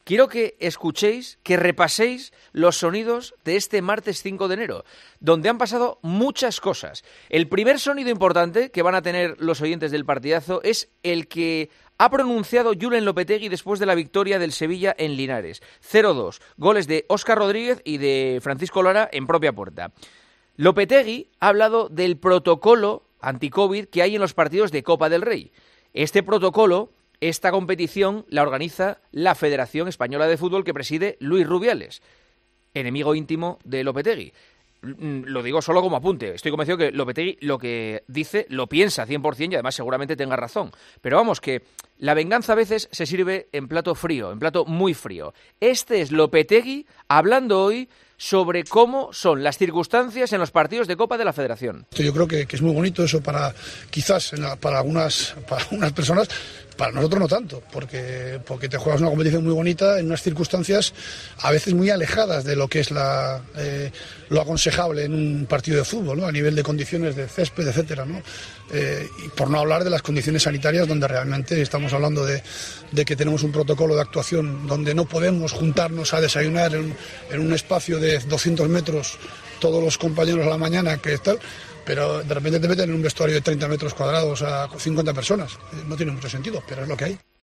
"Los equipos que juegan en esta situación afrontan el partido de forma diferente. Eso es muy bonito, pero para nosotros no tanto, ya que juegas en diferentes condiciones y con un protocolo en el que no podemos juntarnos a desayunar y luego te meten en un vestuario de 30 metros cuadrados con 50 personas, pero es lo que hay", declaró en rueda de prensa.